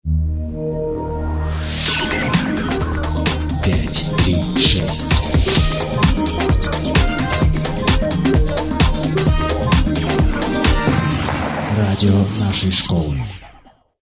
Джингл для школьного радио Категория: Написание музыки